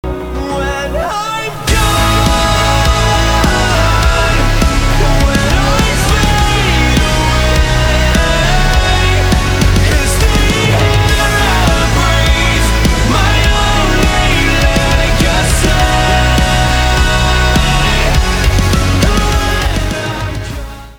• Качество: 320, Stereo
мужской вокал
Alternative Metal
post-grunge
христианский рок